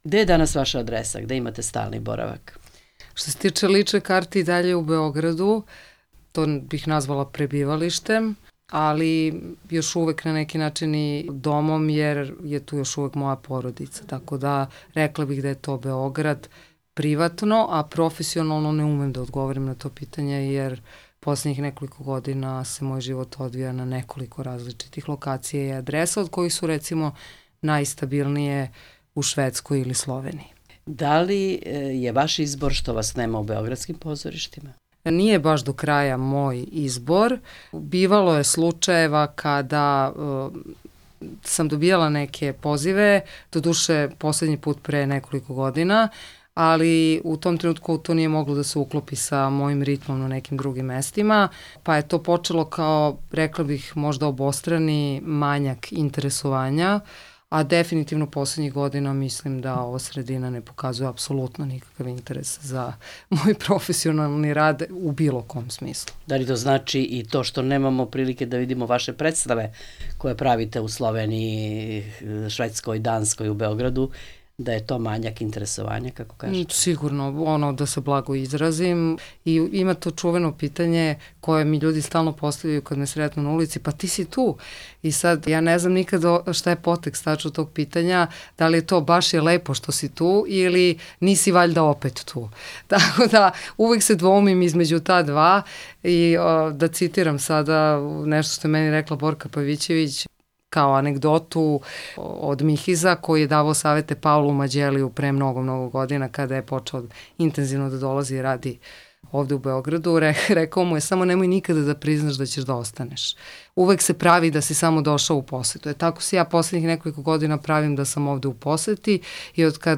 Intervju nedelje: